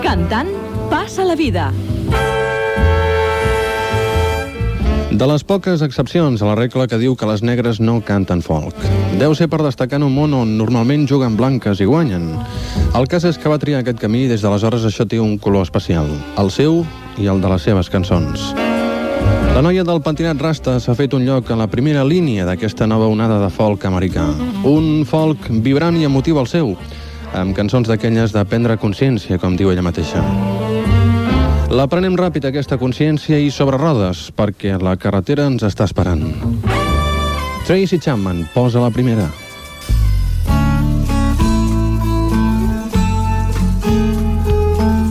Presentació d'un tema musical
Musical
FM